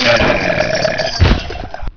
death2.wav